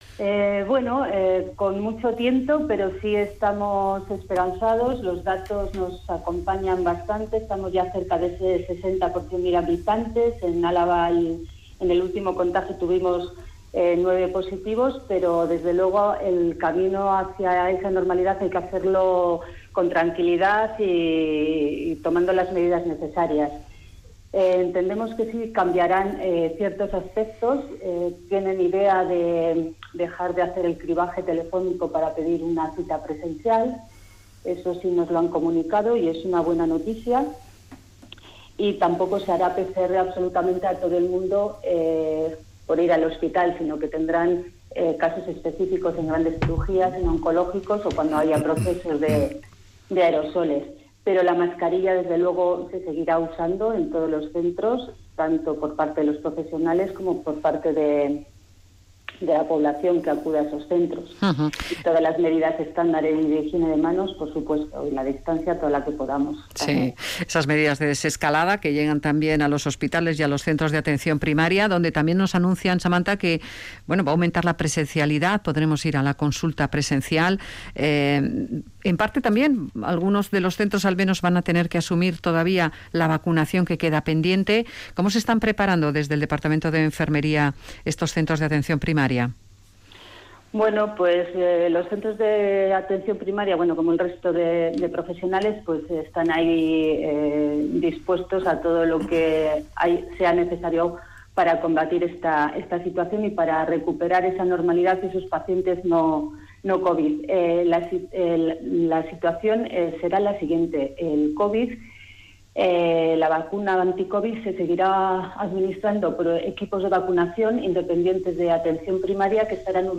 Entrevistada en Radio Vitoria